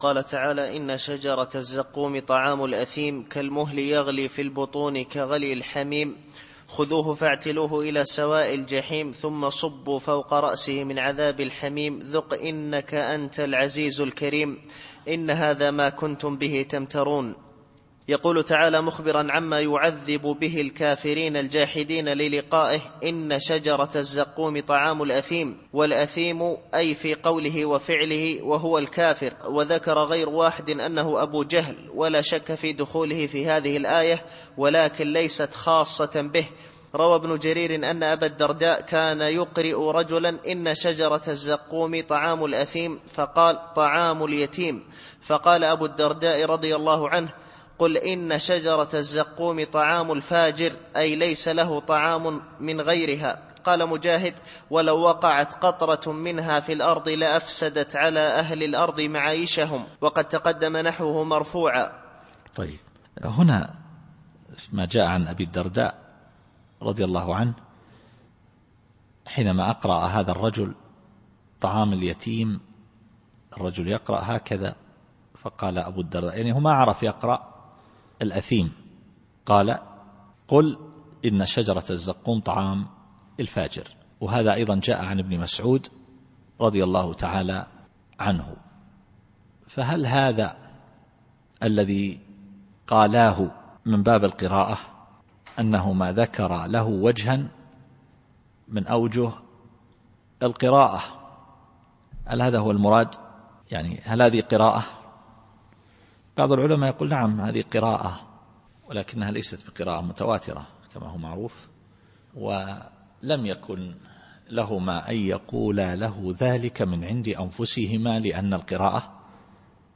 التفسير الصوتي [الدخان / 43]